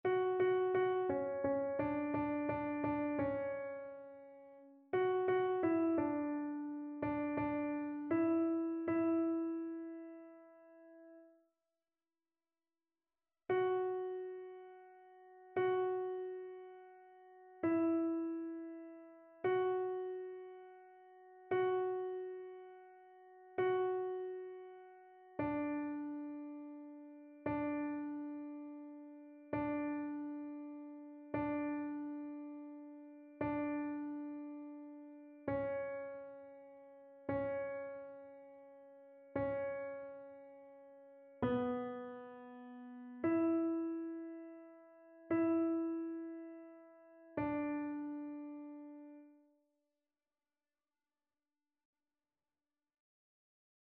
annee-b-temps-pascal-4e-dimanche-psaume-117-alto.mp3